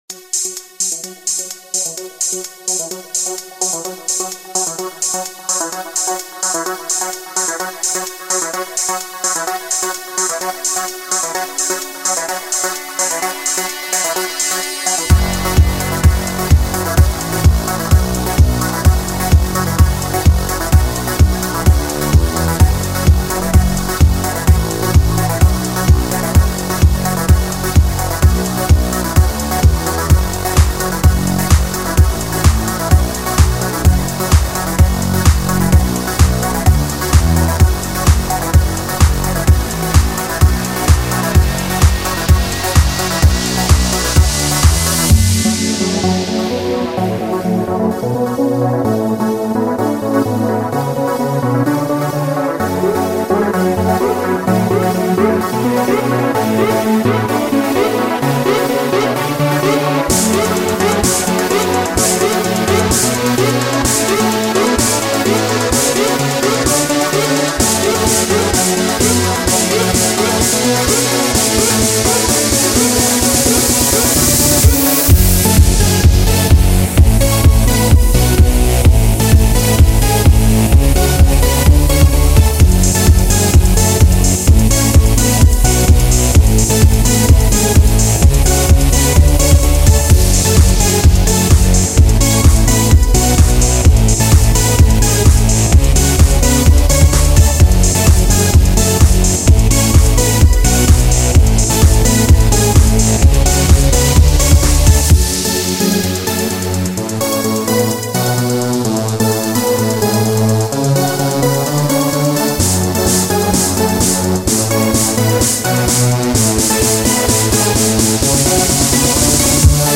genre:progressive house